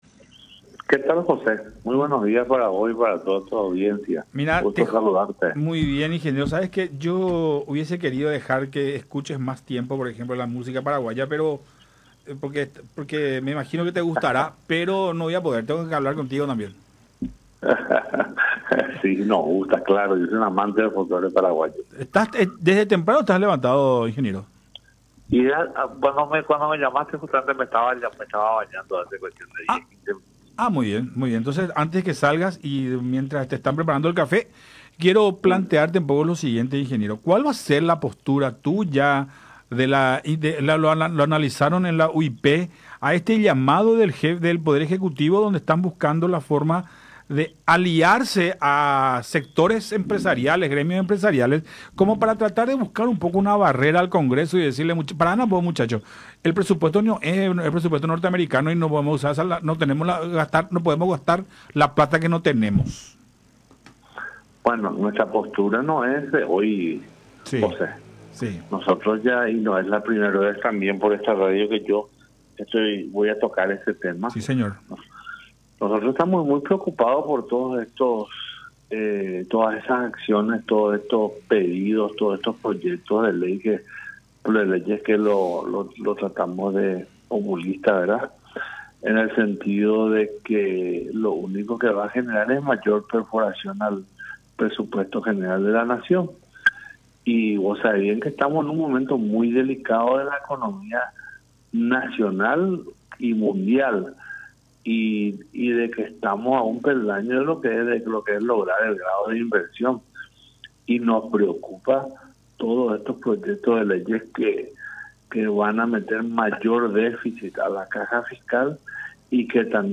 en contacto con La Unión Hace La Fuerza por Unión TV y radio La Unión